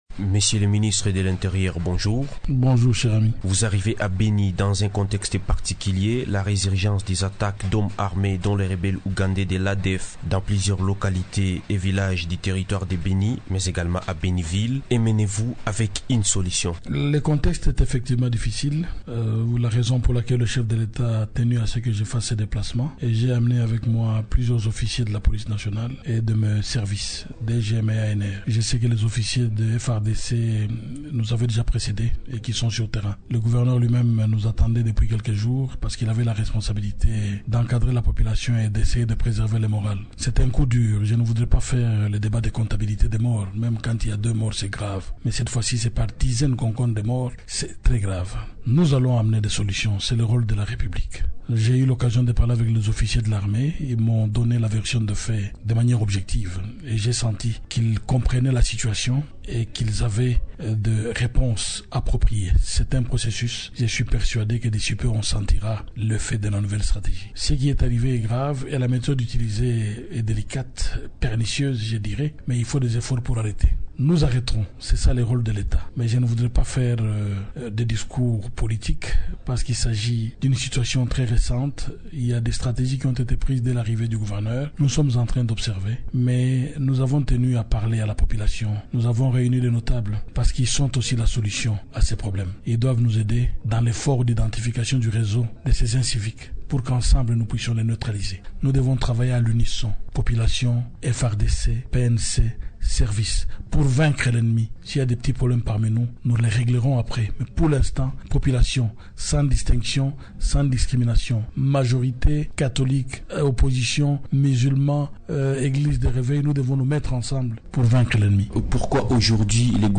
Le gouvernement congolais affirme avoir déjà des solutions et stratégies pour mettre fin aux tueries des civils dans tout le territoire de Beni. Invité de Radio Okapi ce matin, le ministre de l’Intérieur et Sécurité, Richard Muyej Mangez a exprimé la volonté du gouvernement au cours de sa visite à Beni-ville.
interview-Ricahrd-Muyej-Invite-du-jour.mp3